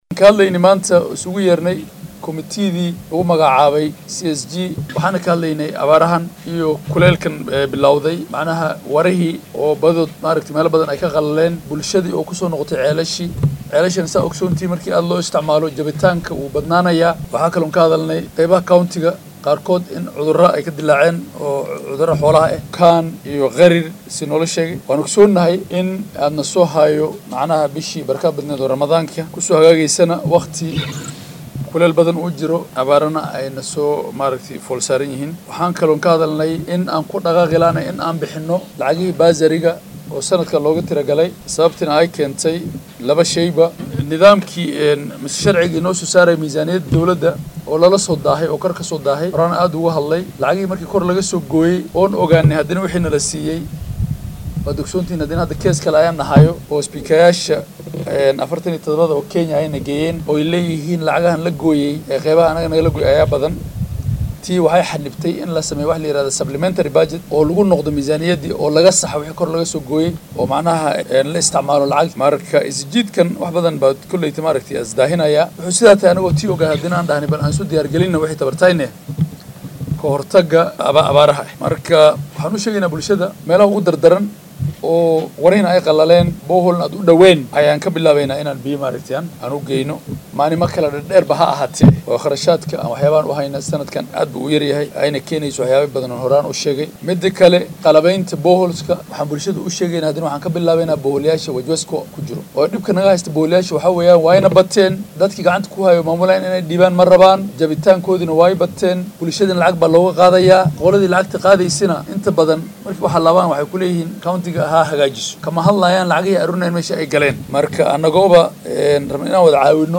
Guddoomiyaha ayaa shir jaraaid oo uu qabtay uga hadlay arrimo dhowr ah waxaa uuna yiri